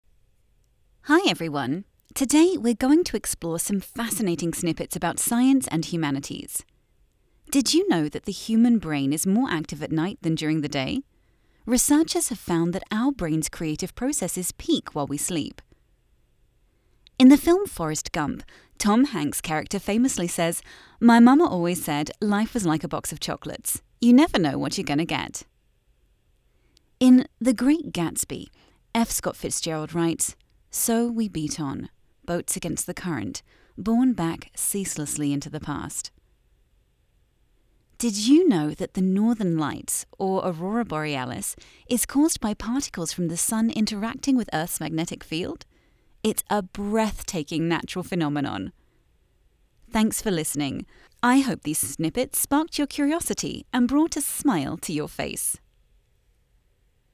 Where voices are not provided, as is typically the case for open source models, we use voices clips from professional voice actors as source files for generating speech.